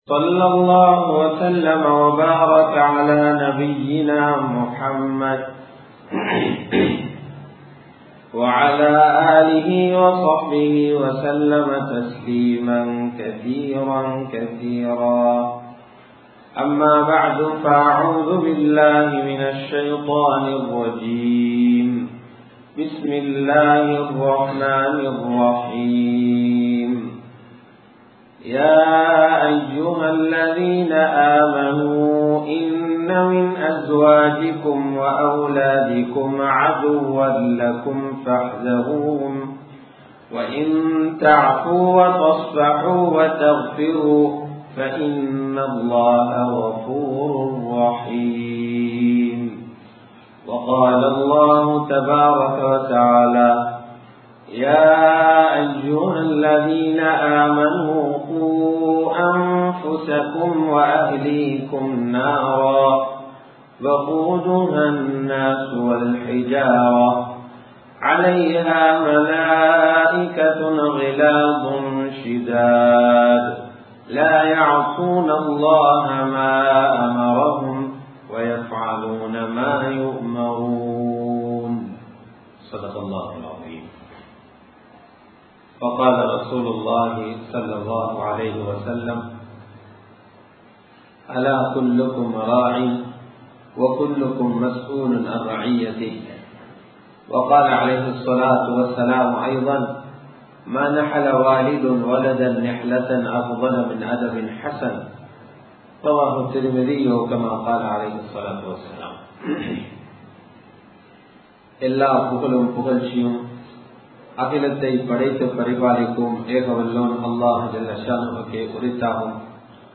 Pillaihalukku Porupputhaarihal Pettroarhale (பிள்ளைகளுக்கு பொறுப்புதாரிகள் பெற்றோர்களே) | Audio Bayans | All Ceylon Muslim Youth Community | Addalaichenai
Colombo 11, Samman Kottu Jumua Masjith (Red Masjith)